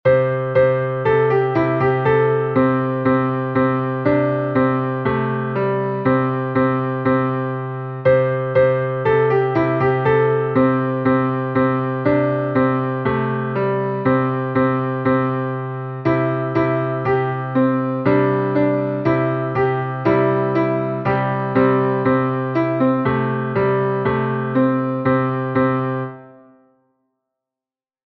Meter: Irregular
Key: C Major